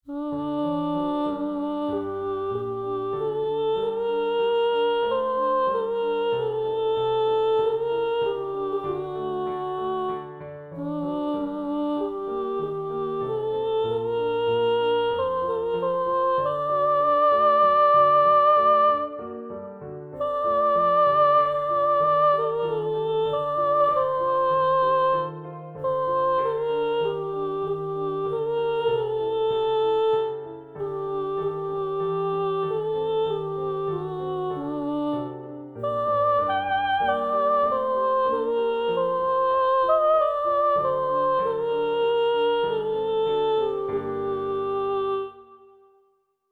Hess 190 / 207 – Varianti, versioni alternative ed escluse di canti popolari di diversi paesi – Hess 191 – Finale di un pezzo sconosciuto per canto e pianoforte; 9 battute in 4/4 in sol minore.
Hess-191-Canzone-Popolare-Gallese-trascrizione-letterale-da-manoscritto.mp3